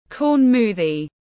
Sinh tố ngô tiếng anh gọi là corn smoothie, phiên âm tiếng anh đọc là /kɔːn ˈsmuː.ði/